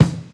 • Clear Kickdrum Sample E Key 186.wav
Royality free kick tuned to the E note. Loudest frequency: 426Hz
clear-kickdrum-sample-e-key-186-EGA.wav